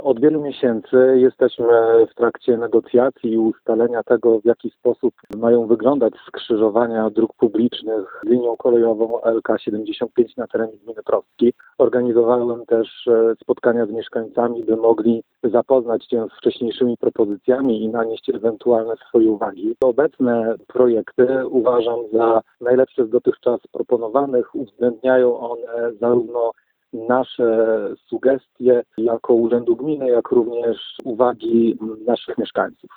Jak mówi wójt gminy Prostki, Rafał Wilczewski, to najlepsze z proponowanych dotąd rozwiązań.